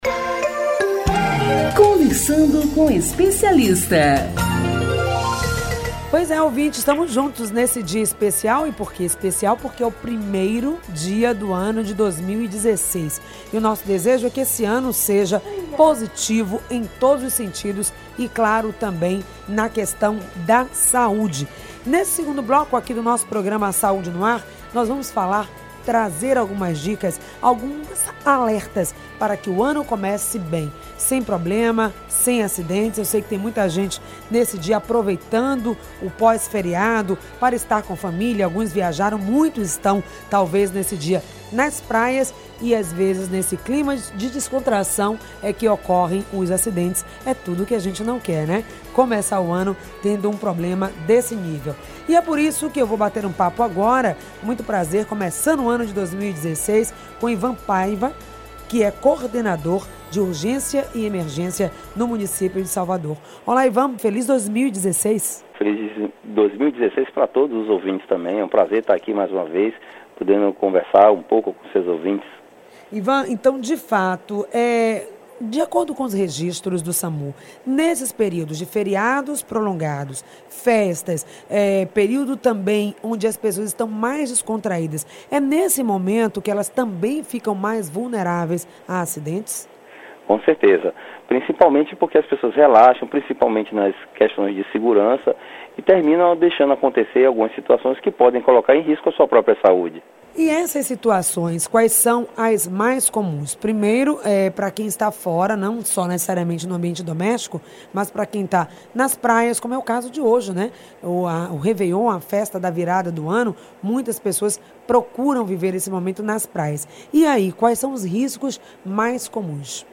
O programa exibido pela rádio am 840 (em 04.01.16, das 10 às 11h) abordou assuntos como: queimaduras, queda, afogamento, primeiros socorros, acidentes no trânsito, entre outros.